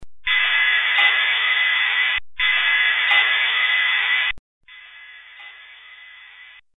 Audio recorded while walking through the basement of Knight Hall on the campus of the University of Wyoming. You can hear the steam running through the pipes in all of these clips. They were recorded with an RCA Digital Recorder Model # RP5011A.
"Loud and Soft Bangs and
Clanks" After saying a few words about the basement, there are a series of mysterious bangs and clanks.
The clank audio's last loop has had noise reduction applied to it.
Basement_Pipehit.mp3